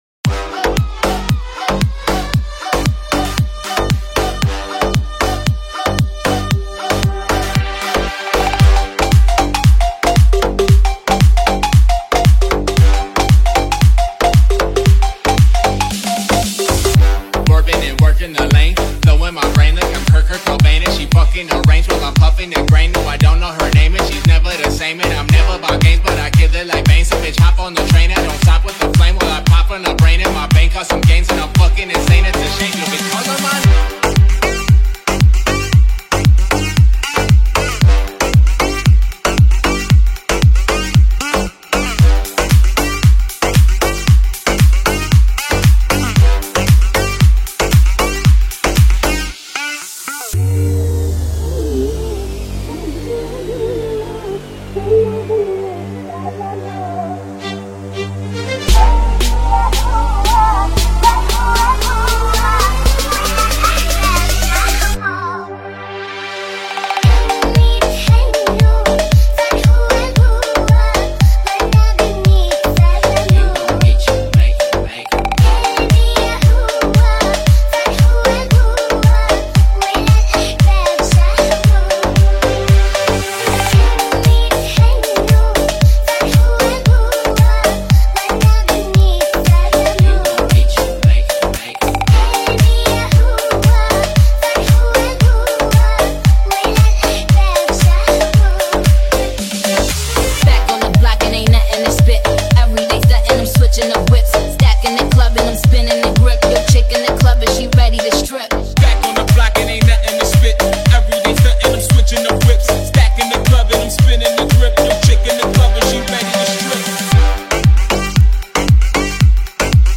Bass Boosted Oriental Trap
Epic Eastern vibes with powerful bass drops